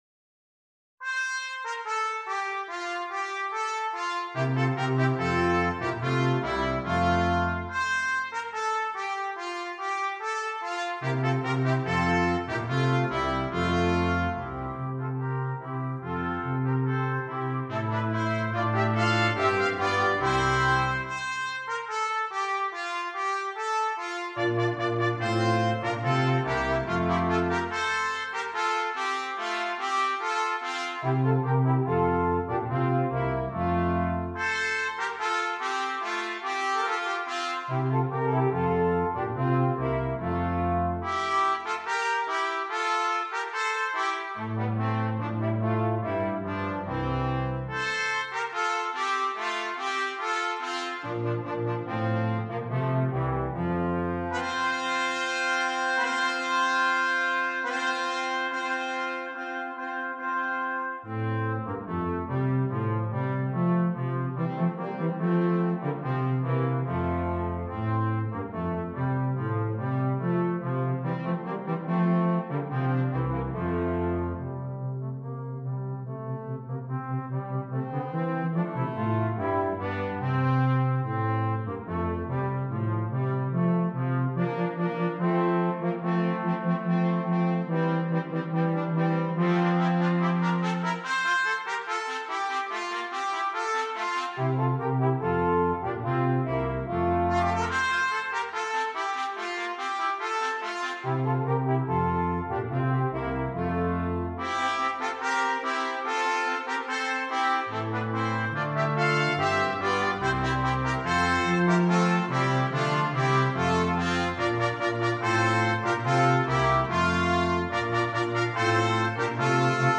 Brass Quintet
Traditional Carol